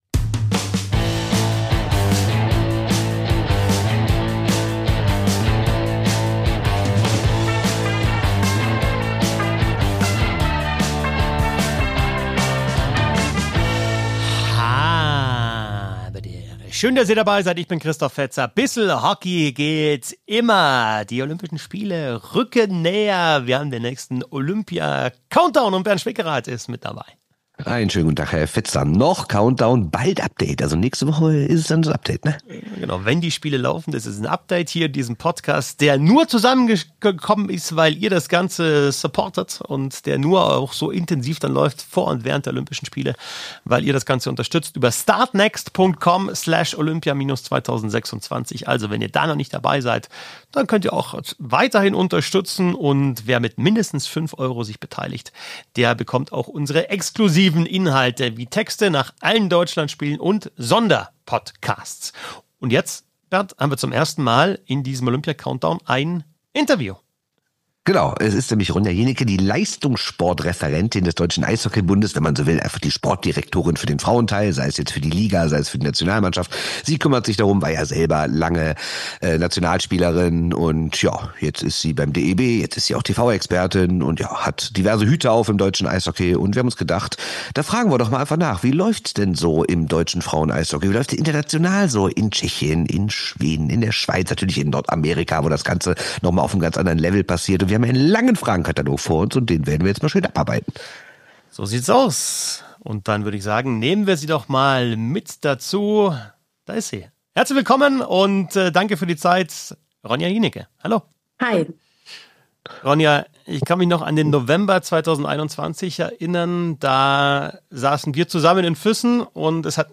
Interview ~ bissl Hockey Podcast